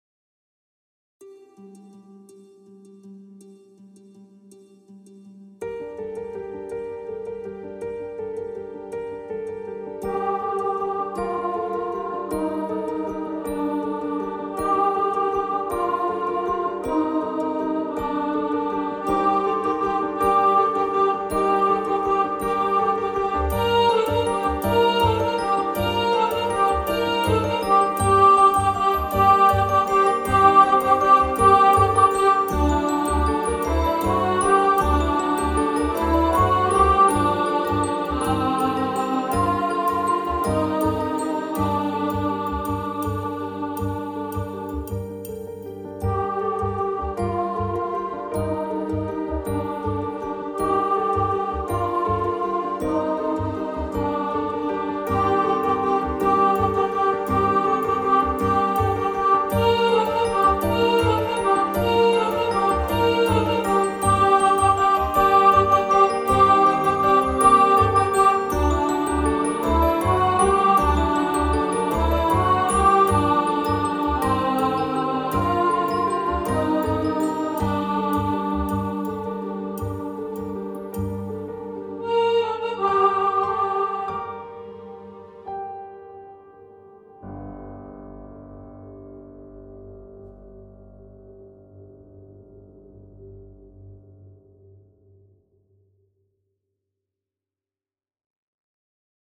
Carol-Of-The-Bells-Alto.mp3